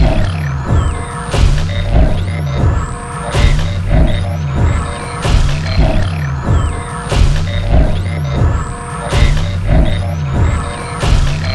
lark.wav